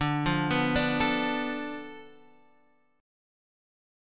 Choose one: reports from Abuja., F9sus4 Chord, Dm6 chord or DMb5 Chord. Dm6 chord